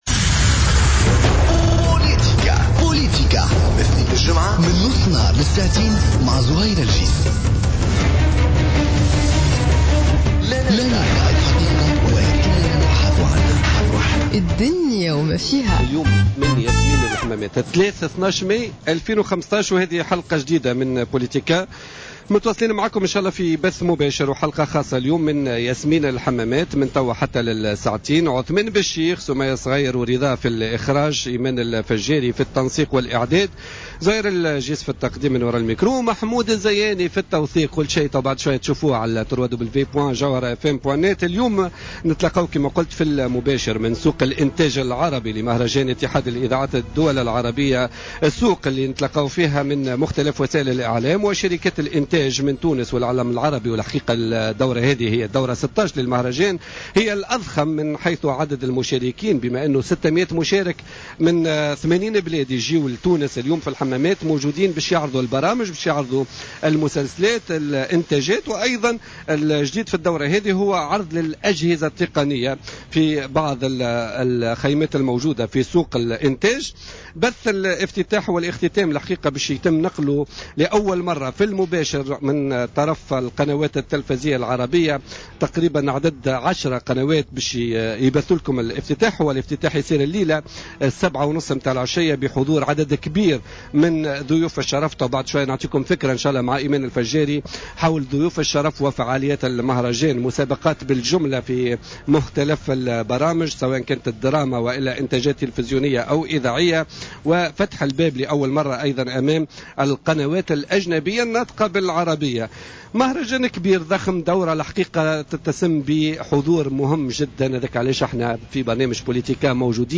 مباشرة من الحمامات : انطلاق فعاليات المهرجان العربي للإذاعة والتلفـزيون في دورته السادسة عشر